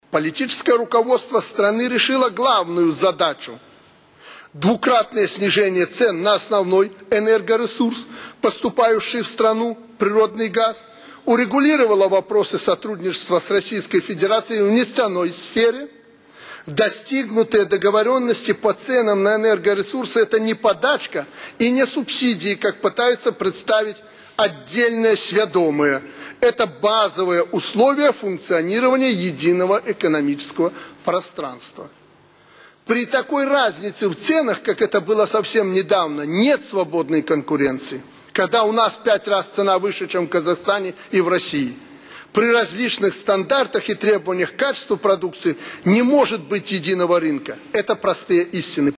Аляксандар Лукашэнка. Пасланьне да беларускага народу і Нацыянальнага сходу. 8 траўня 2012